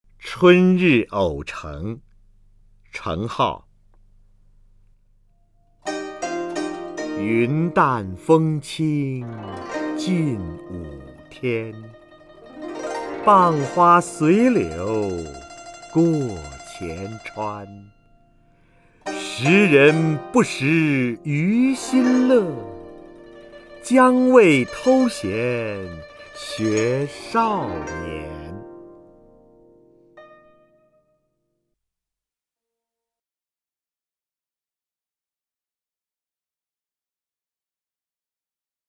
瞿弦和朗诵：《春日偶成》(（北宋）程颢) （北宋）程颢 名家朗诵欣赏瞿弦和 语文PLUS
（北宋）程颢 文选 （北宋）程颢： 瞿弦和朗诵：《春日偶成》(（北宋）程颢) / 名家朗诵欣赏 瞿弦和